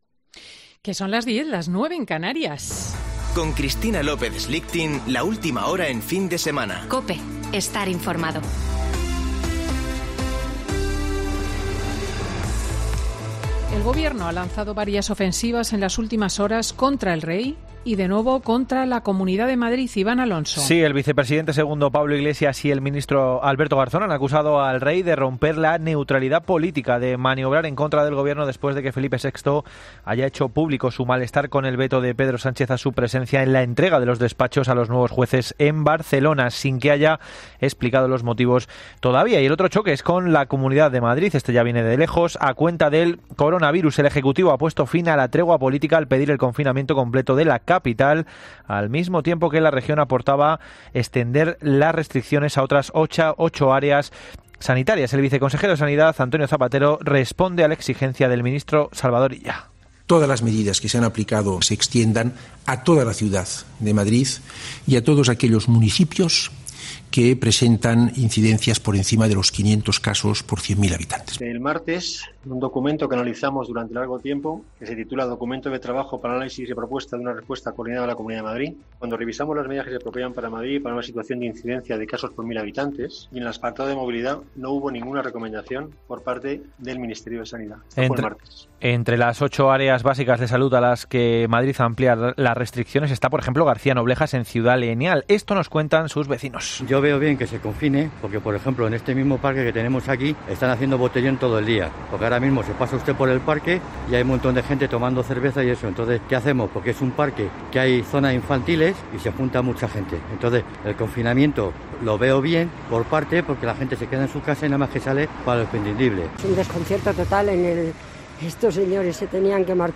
Boletín de noticias de COPE del 26 de septiembre de 2020 a las 10.00 horas